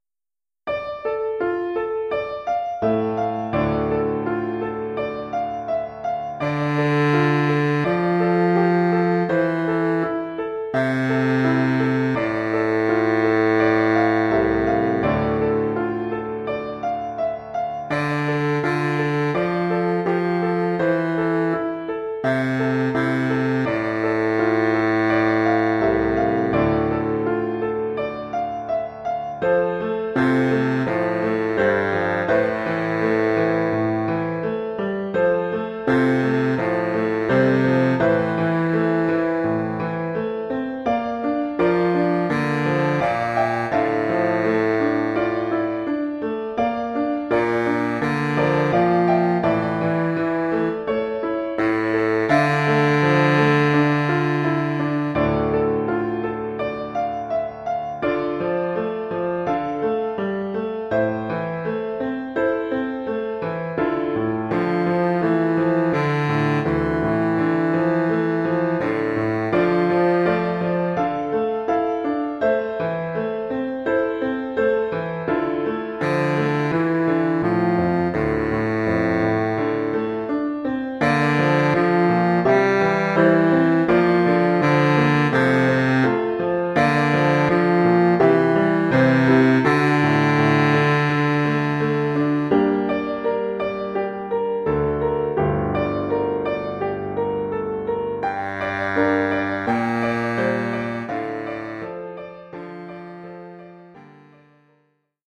Formule instrumentale : Saxophone baryton et piano
Oeuvre pour saxophone baryton et piano.